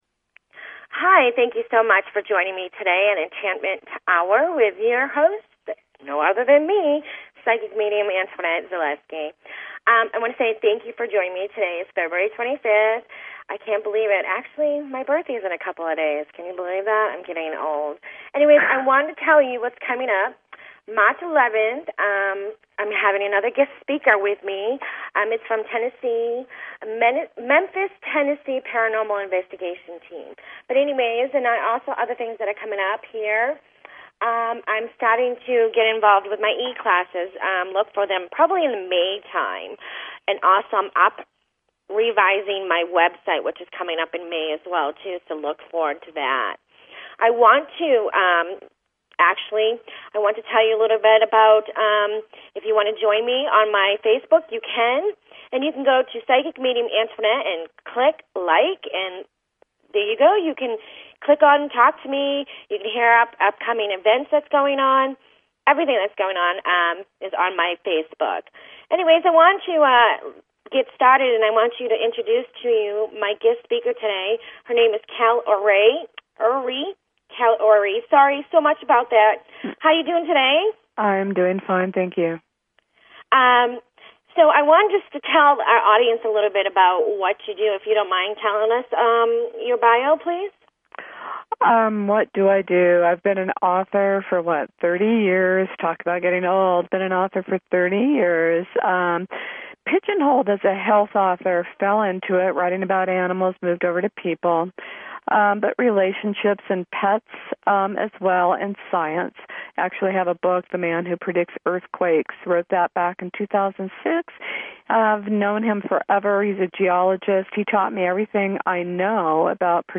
Talk Show Episode, Audio Podcast, Enlightenment_Hour and Courtesy of BBS Radio on , show guests , about , categorized as